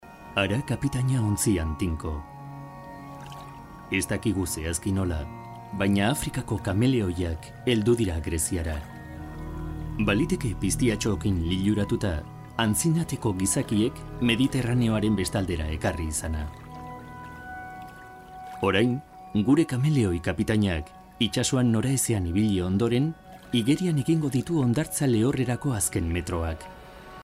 locutor euskara